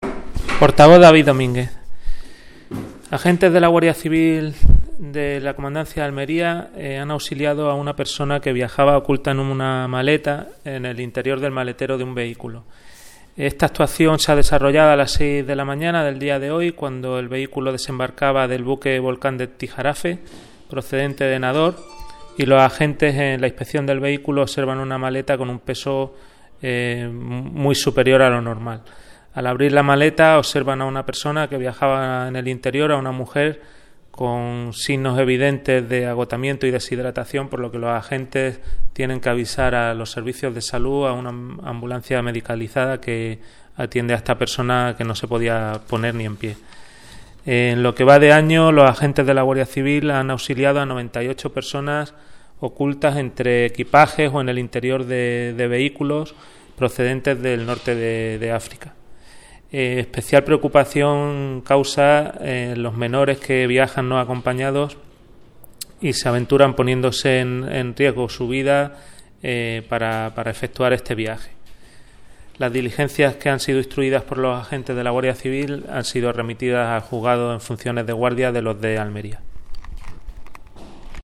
Declaraciones: